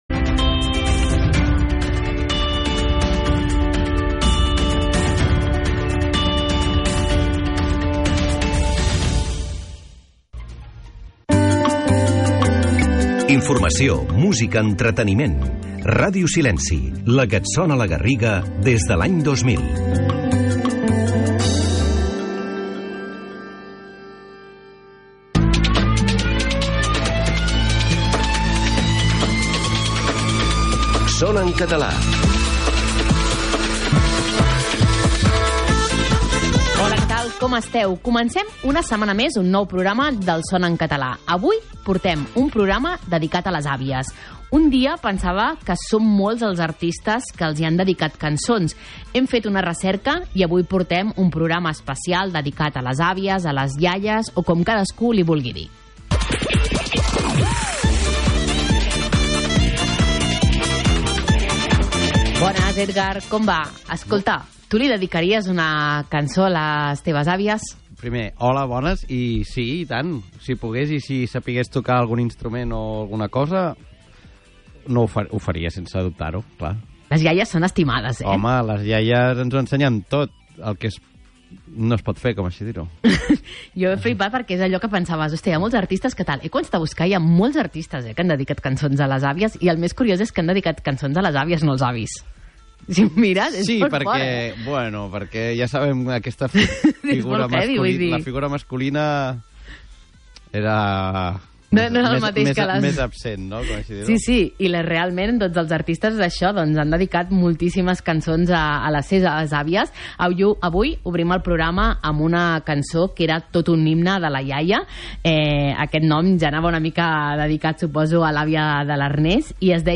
Una hora de música en català amb cançons de tots els temps i estils. Història, actualitat, cròniques de concerts, curiositats, reportatges, entrevistes...